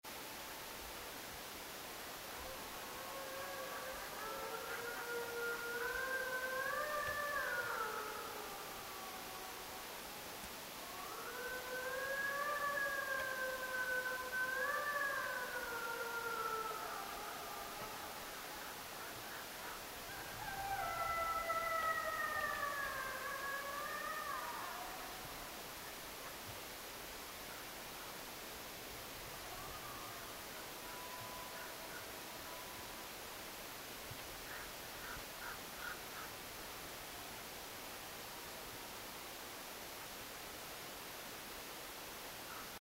Suden ulvonta